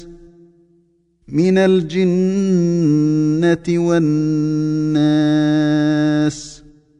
Var och en av dem bör hållas under en tidsperiod, lika med den andra, och förlänga ljudet med en ghunnah som avges från näsan.